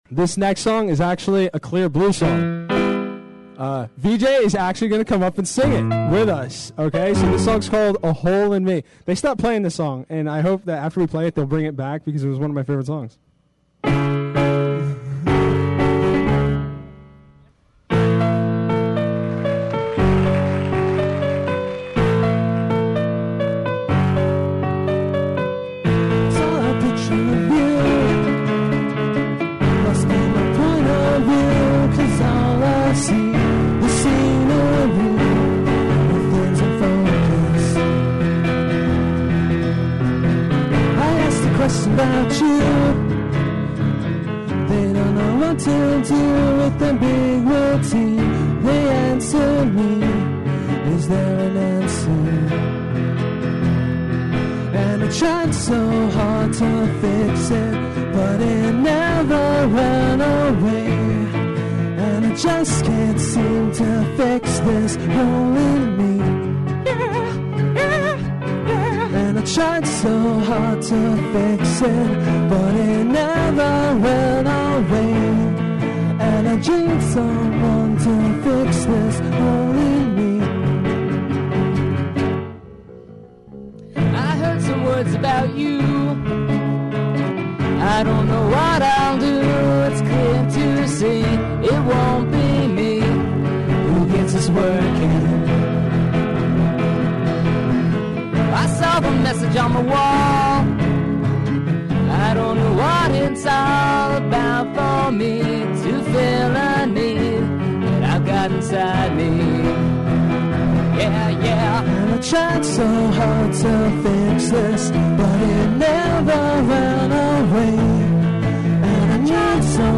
live acoustic cover
ridiculous background vocals